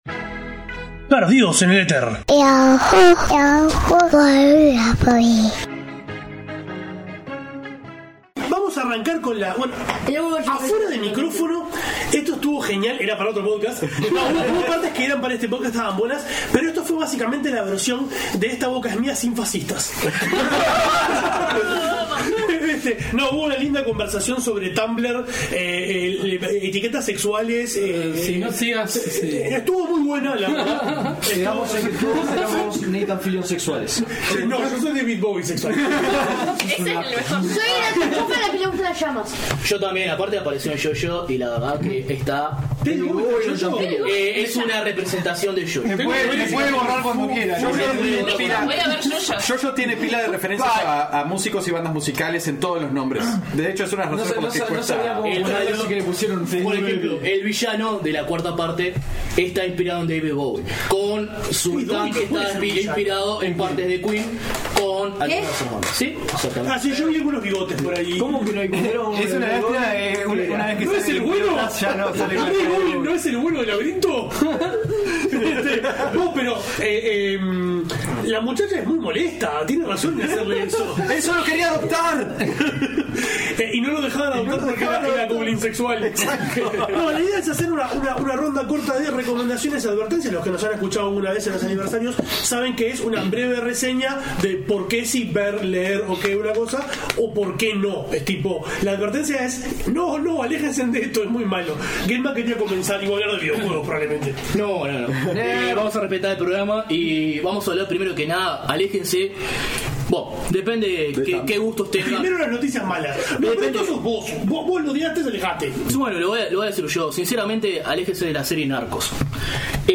Quedó un programa GIGANTE, con muchos invitados.
En esta segunda parte, tuvimos una serie de Recomendaciones y Advertencias, que degeneró en una fiesta de apreciacion de One Punch Man, además de un Zapping con un pirata espacial. Por último, tenemos los bloopers de los Zappings 2015/2016.